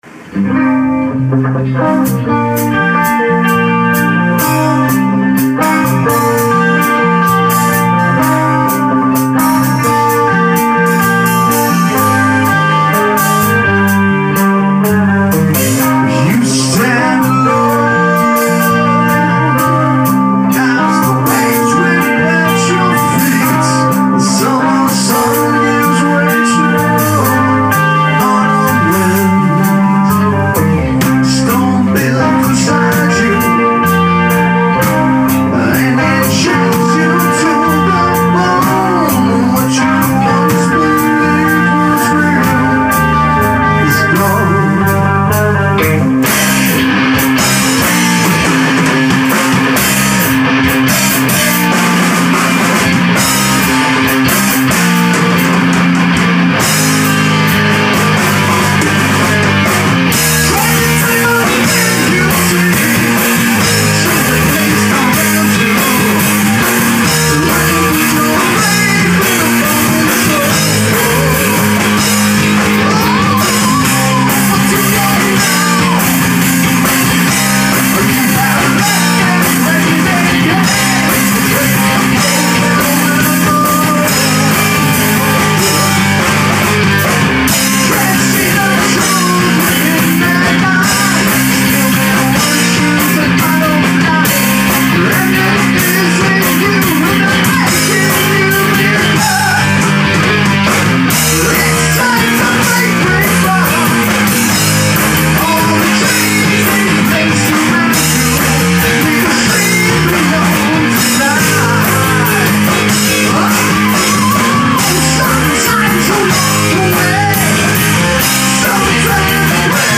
ATR – Rehearsal 8/15